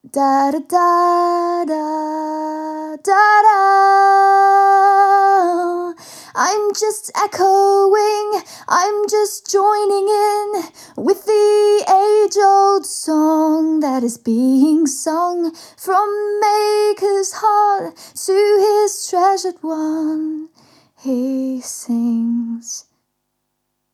RodzajDynamiczny
Charakterystyka kierunkowaKardioidalna
Próbki dzwiękowe Audio Technica AE 4100
Audio Technica AE4100 - damski wokal